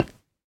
Minecraft Version Minecraft Version 1.21.5 Latest Release | Latest Snapshot 1.21.5 / assets / minecraft / sounds / block / bone_block / step2.ogg Compare With Compare With Latest Release | Latest Snapshot
step2.ogg